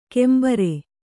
♪ kembare